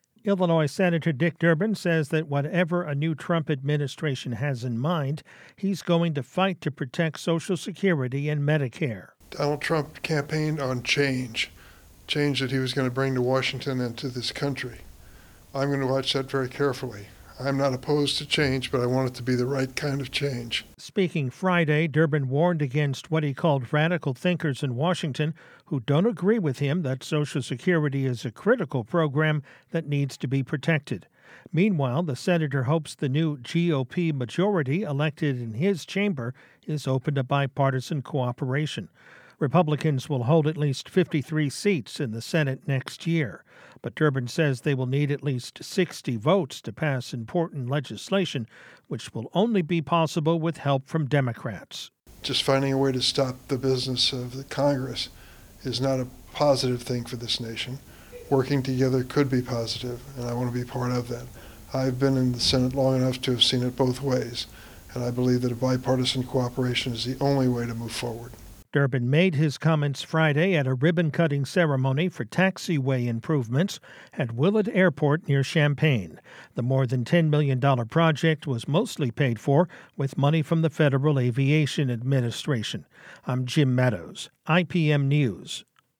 Senator Richard Durbin of Illinois talks with reporters at Willard Airport near Champaign on the Friday after Election Day.
The Springfield Democrat made his comments Friday at Willard Airport near Champaign.
In a brief news conference that followed, Durbin brought up the matter of Trump’s election victory himself.